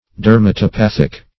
Search Result for " dermatopathic" : The Collaborative International Dictionary of English v.0.48: Dermatopathic \Der`ma*to*path"ic\, a. [Gr. de`rma, -atos, skin + pa`qos suffering.]
dermatopathic.mp3